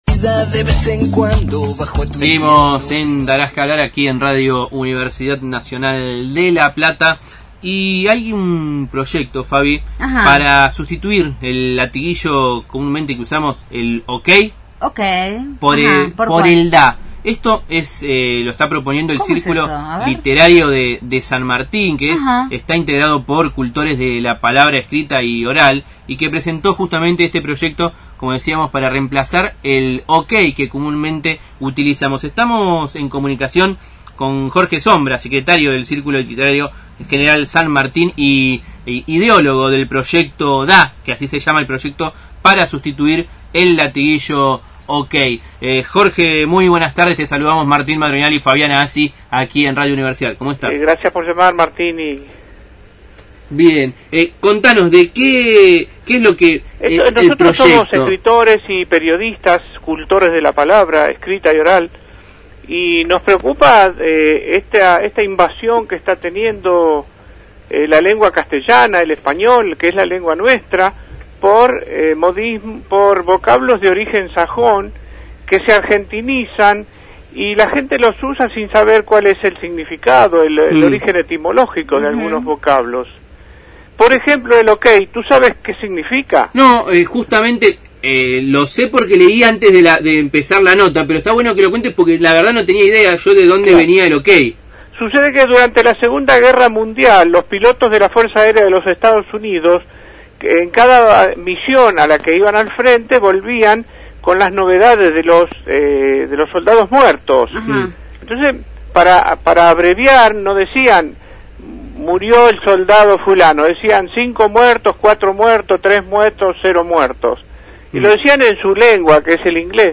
dialogó con el equipo de «Darás que hablar» sobre el «Proyecto DA», por el que quieren sustituir el OK (cero killers) por DA (de acuerdo).